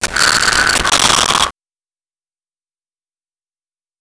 Clock Loop 1
Clock_loop_1.wav